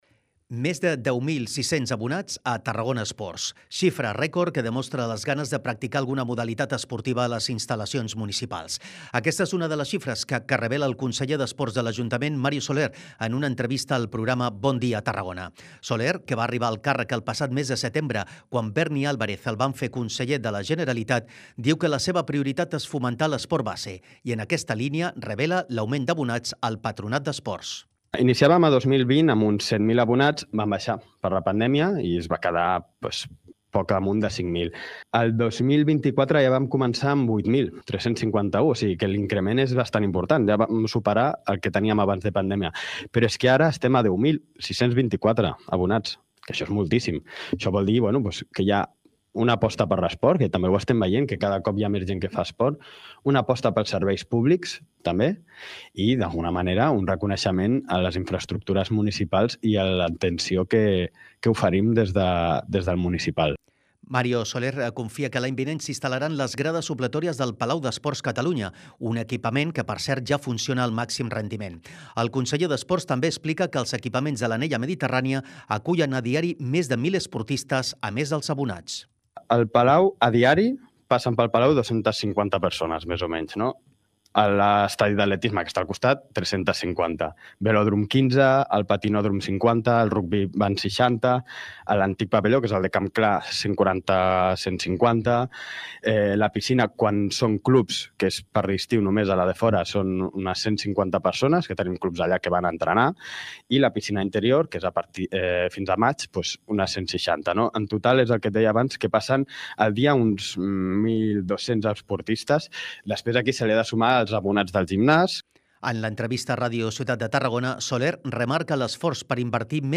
Aquesta és una de les xifres que revela el conseller d’Esports de l’Ajuntament, Mario Soler, en una entrevista al programa ‘Bon dia Tarragona’.